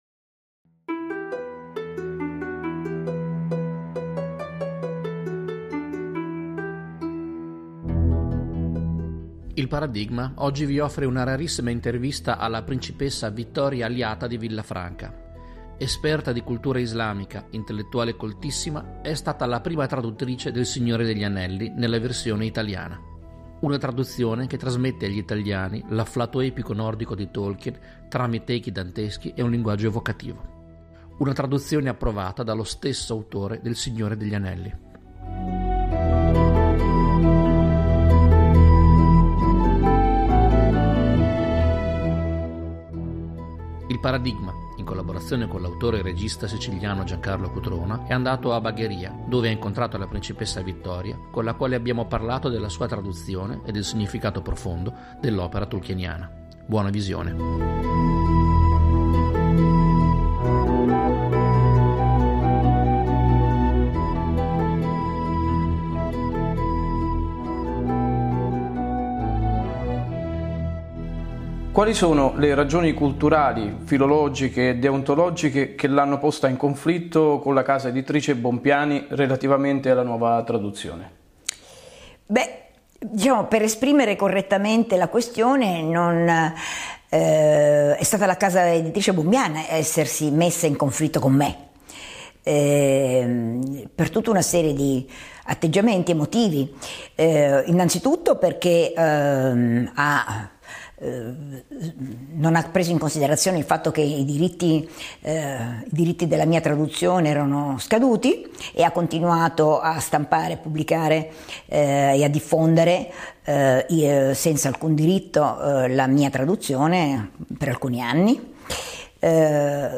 In occasione di questo Natale 2020, ilParadigma propone un'interessante intervista alla principessa Vittoria Alliata di Villafranca; autrice e prima traduttrice del Signore degli Anelli nonché profonda conoscitrice di cultura tradizionale islamica.